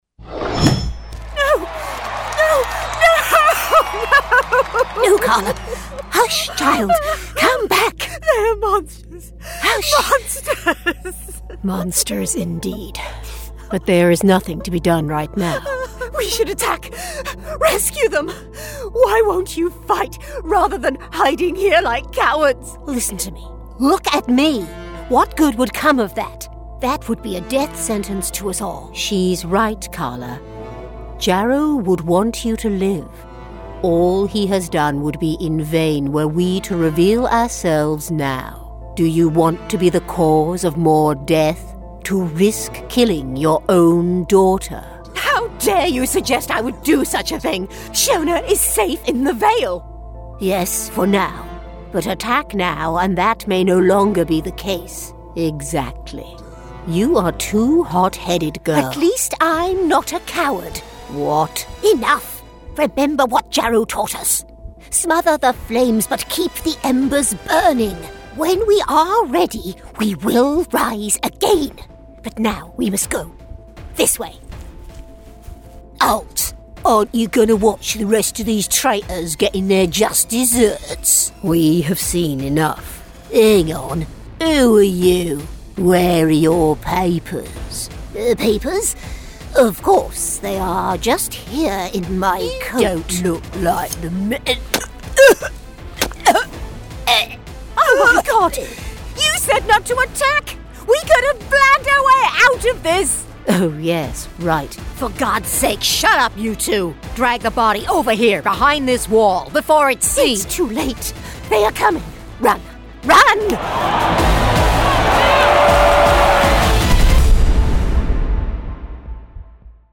An experienced British English voice actor with a warm assured voice and versatility
Gaming Reel